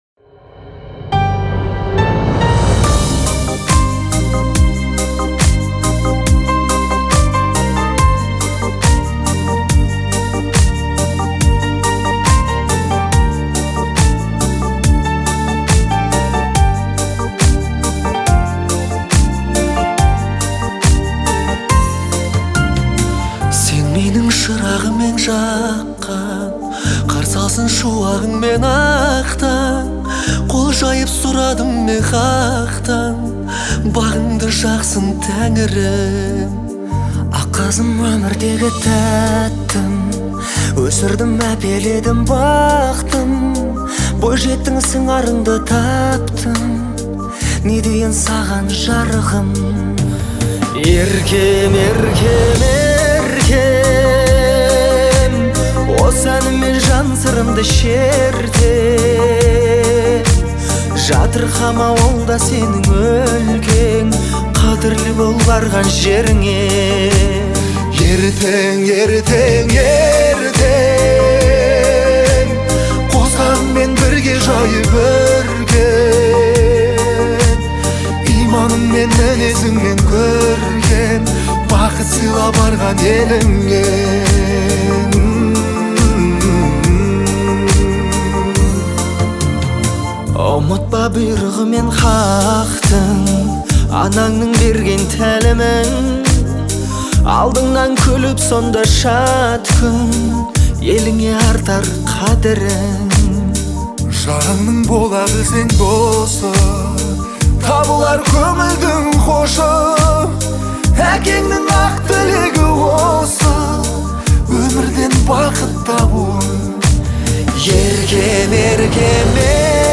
это яркий образец казахского поп-рока